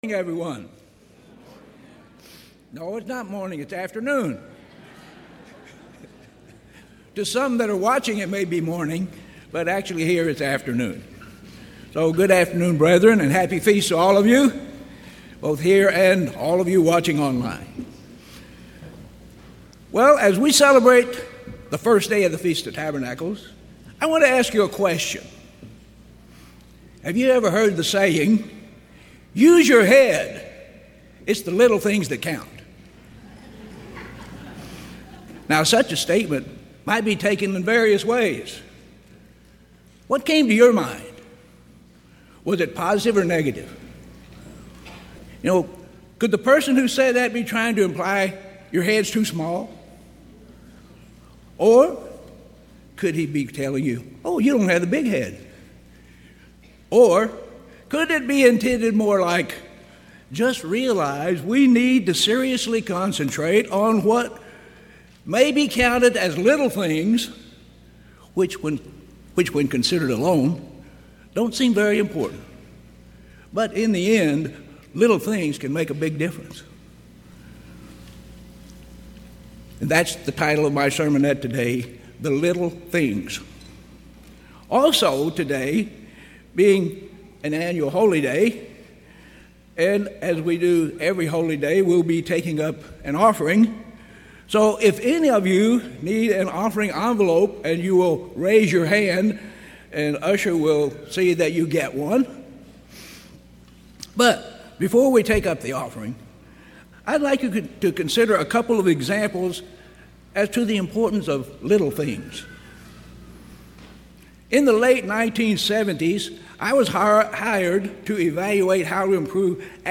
This message was given during the 2024 Feast of Tabernacles in Panama City Beach, Florida.